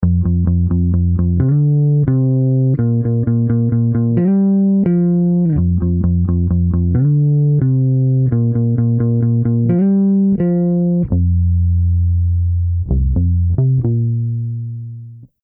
Note: In these examples, Version A is with no effect, Version B is with a large amount of compression, and Version C is also with a large amount of compression, but with the HP Detector enabled, allowing the resulting signal to have more bass.
Bass-Distressor-Thick-Compression.mp3